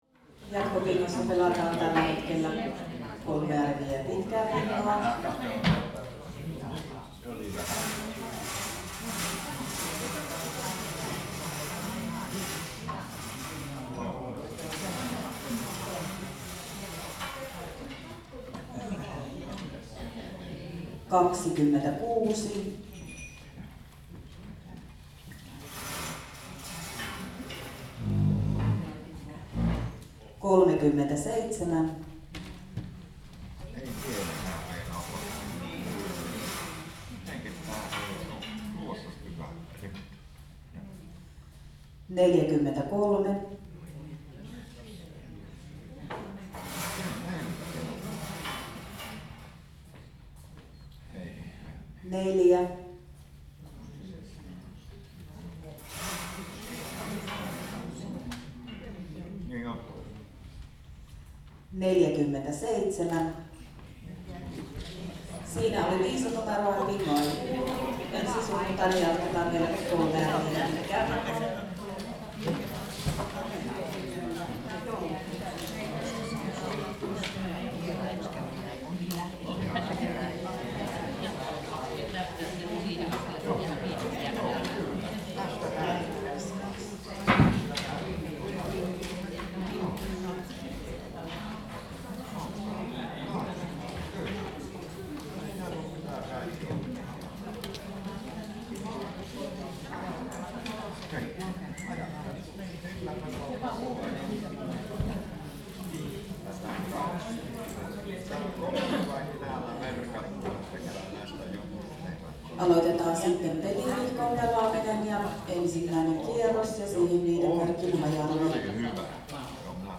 Every Sunday a group of bingo players gather around for a weekly bingo night at Suolahti People’s House.
• bingo machine